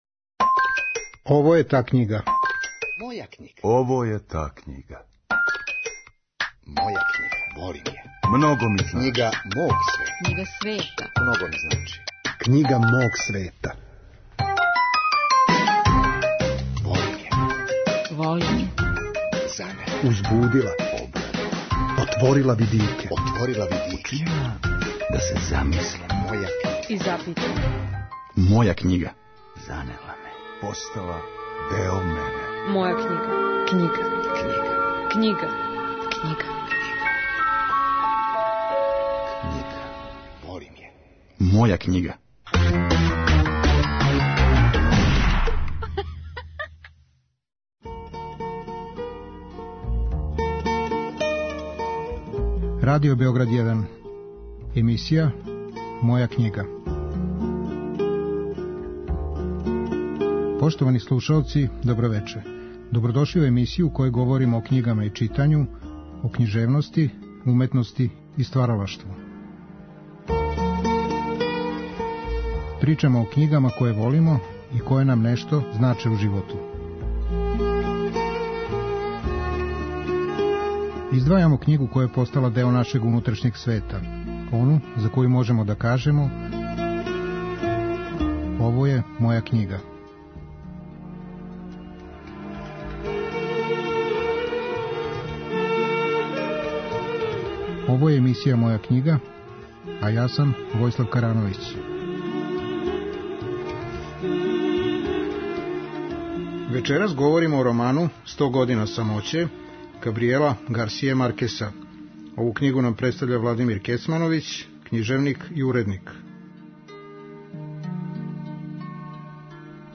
У вечерашњој емисији саговорник нам је Владимир Кецмановић, књижевник и уредник. Он говори о роману ''Сто година самоће'' колумбијског писца Габријела Гарсије Маркеса.